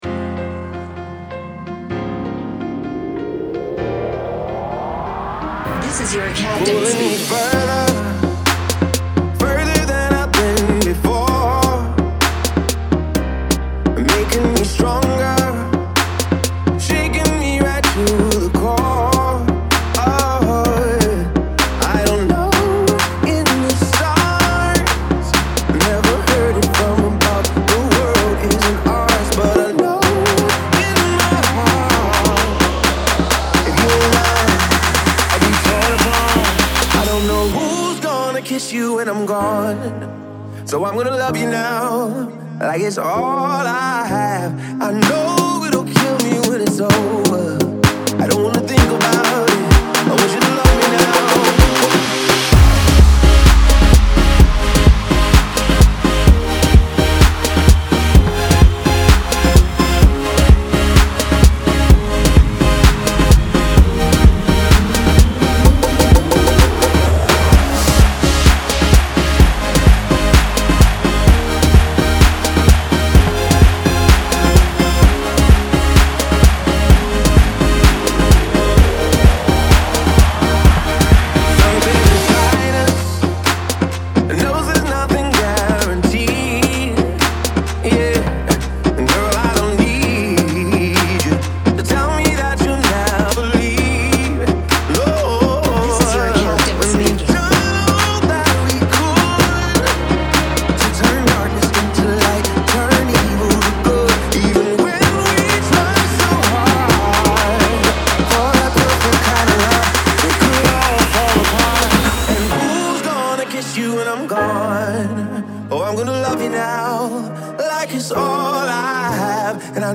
remixed into AfroElectro